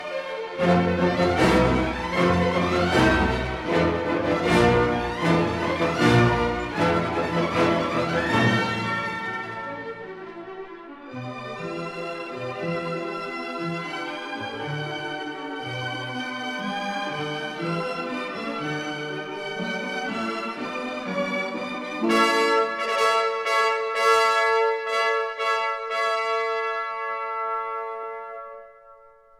1958 stereo recording